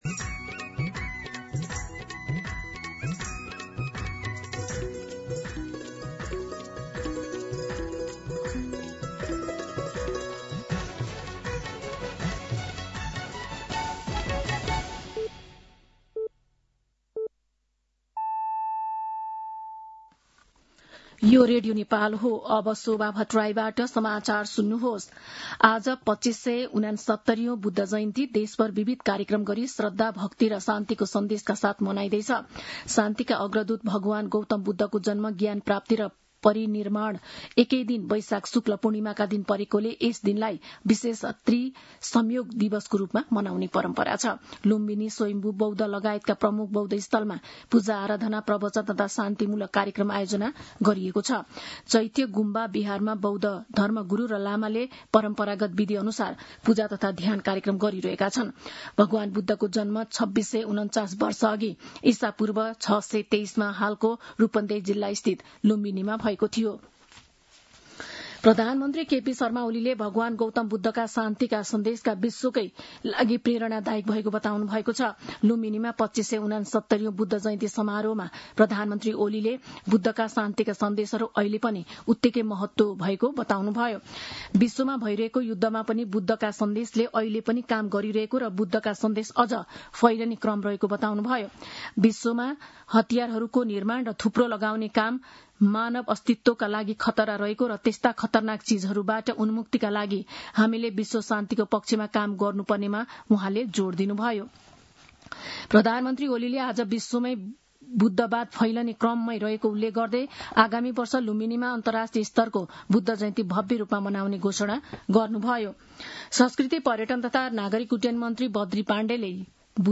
दिउँसो ४ बजेको नेपाली समाचार : २९ वैशाख , २०८२
4pm-Nepali-News-01-29.mp3